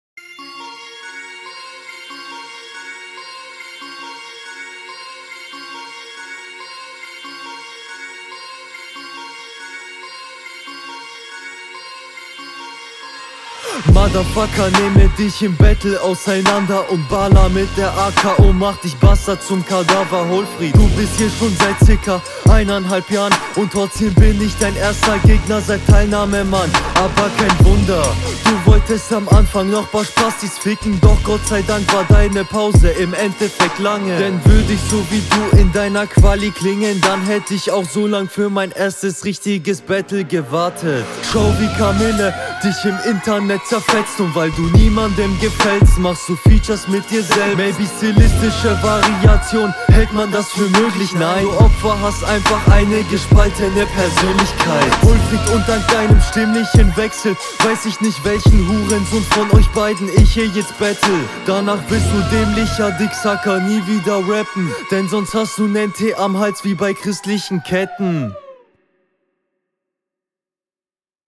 Der Beat liegt dir sehr gut. Flows bocken an vielen Stellen.